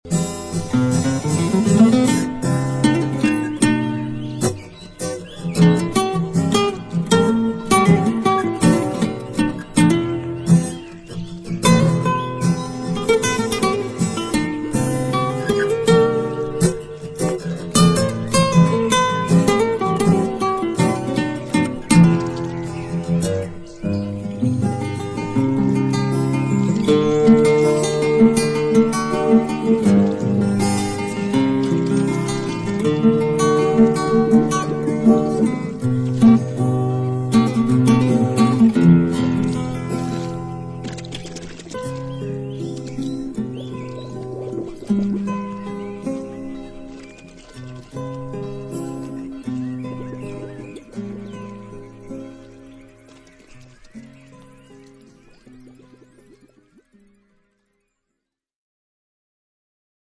cosmic-electro-disco band